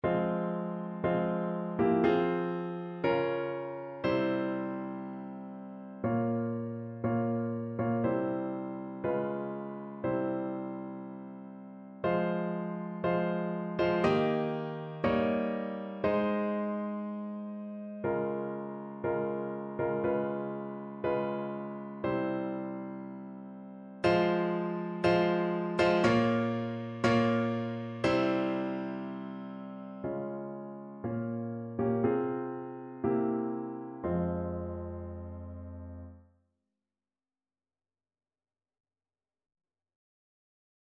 Evangeliumslieder Hingabe
Notensatz (4 Stimmen gemischt)